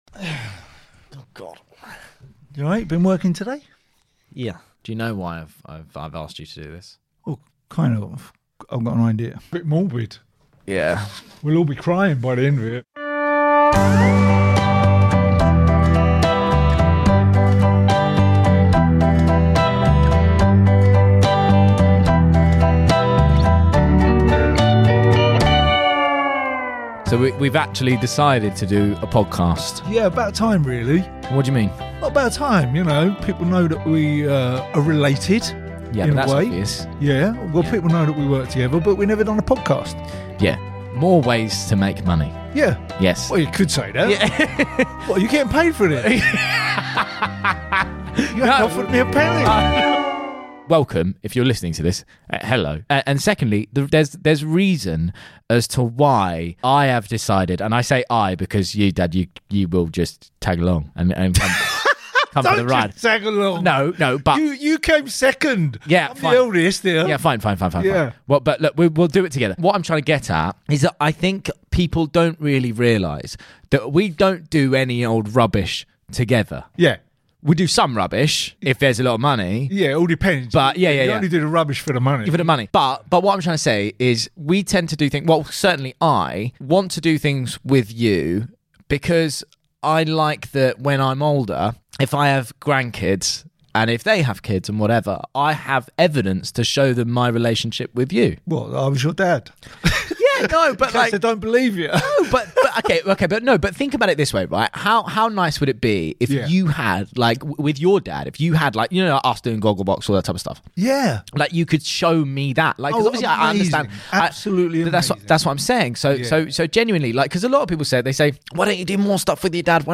My Dad Is Martin Kemp Roman Kemp & Martin Kemp Comedy 4.9 • 1000 Ratings 🗓 30 April 2024 ⏱ 35 minutes 🔗 Recording | iTunes | RSS 🧾 Download transcript Summary Welcome to FFS!